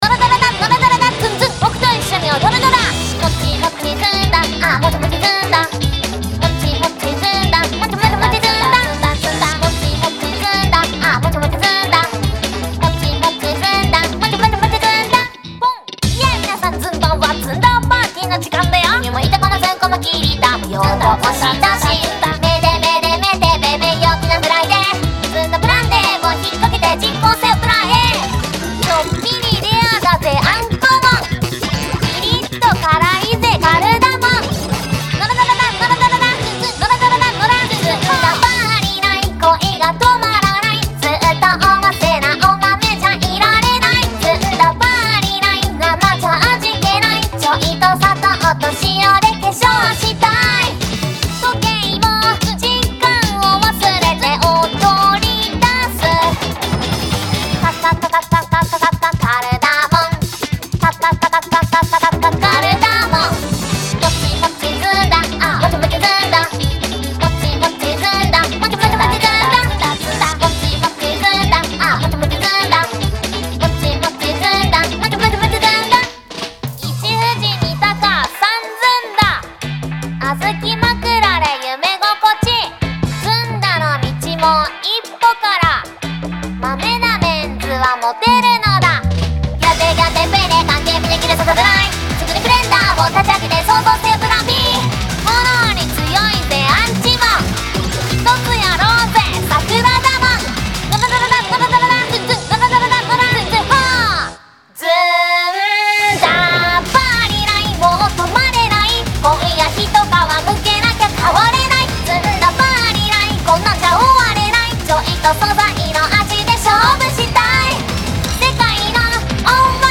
Sample Mix (Voacl Mix)